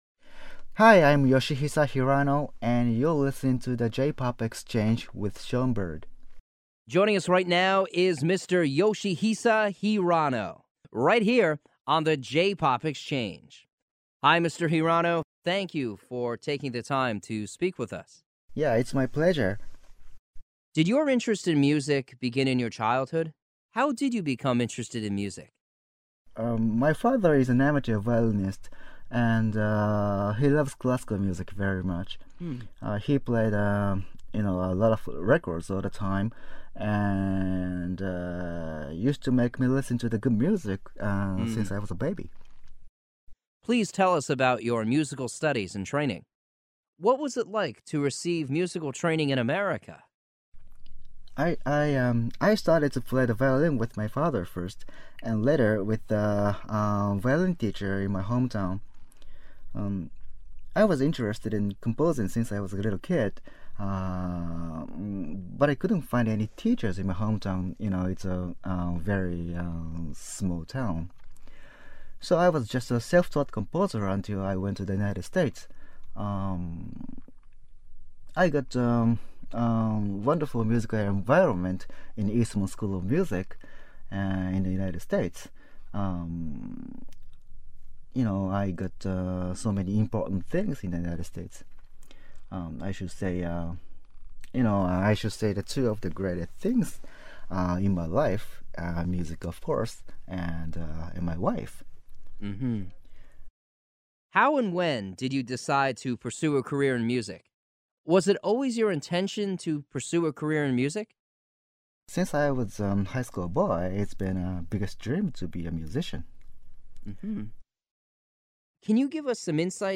The J-Pop Exchange Yoshihisa Hirano Exclusive Interview
Yoshihisa_Hirano_JPop_Exchange_Exclusive_Interview.mp3